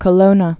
(kə-lōnə)